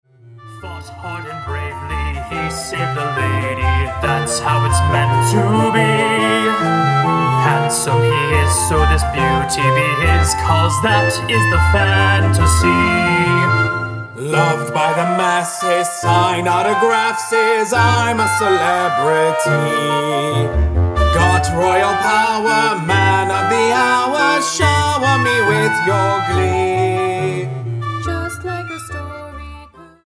Showtunes